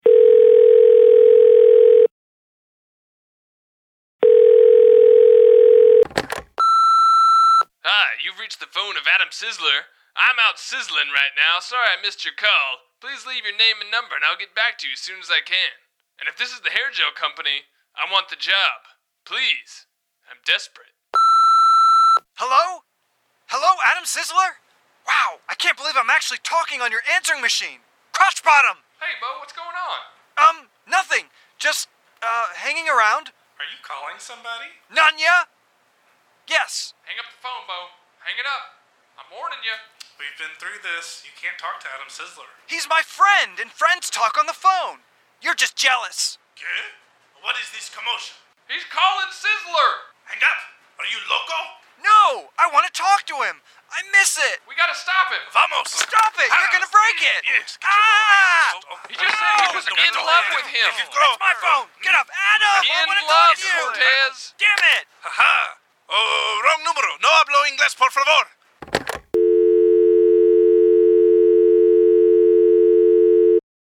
Exhibit B (Sizlewski’s answering machine)